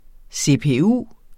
Udtale [ sepeˈuˀ ]